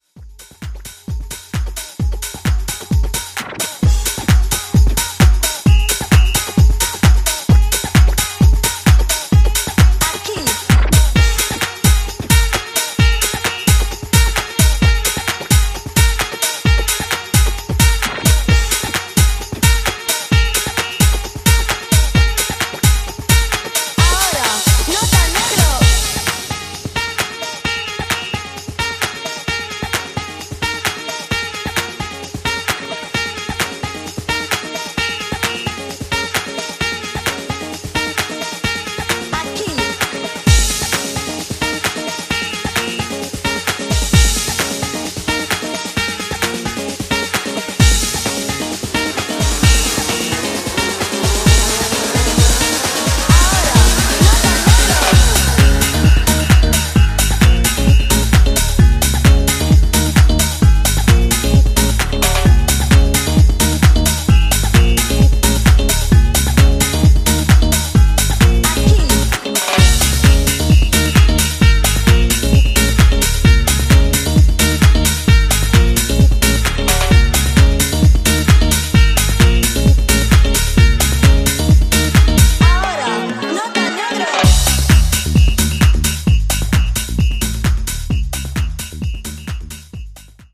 各曲フレッシュな勢いで溢れており、ピークタイムを気持ちよく沸かせてくれることでしょう！